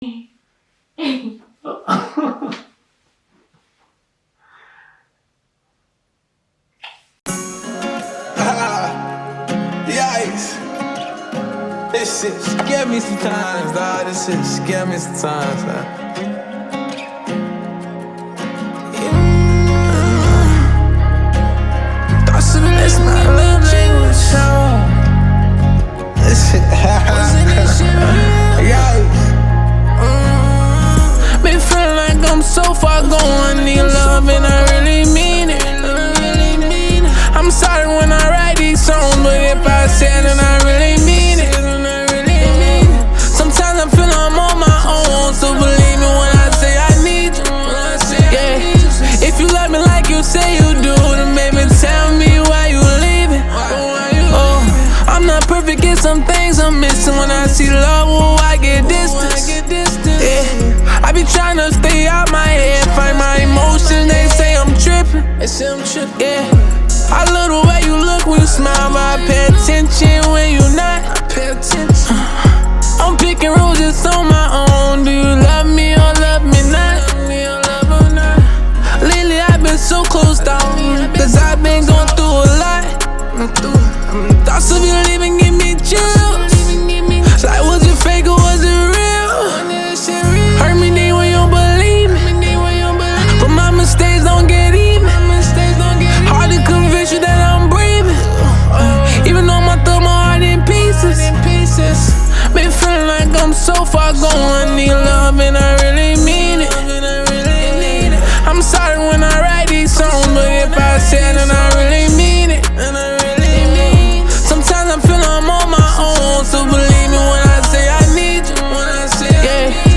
Hip Hop
features soothing atmospheres and tunes